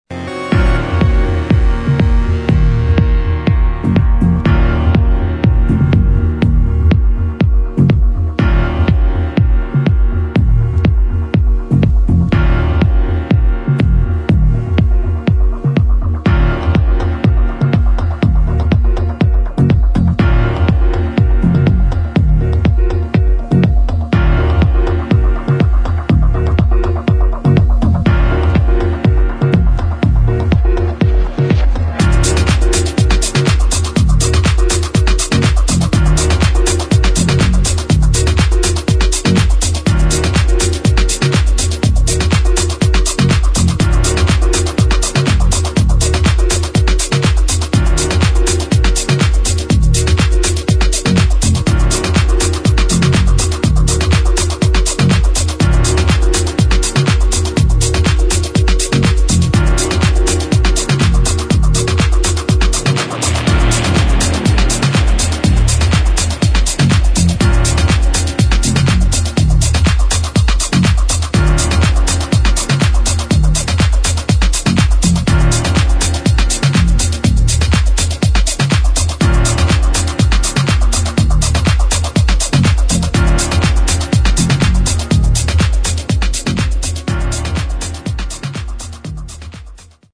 [ DEEP HOUSE / TECH HOUSE ]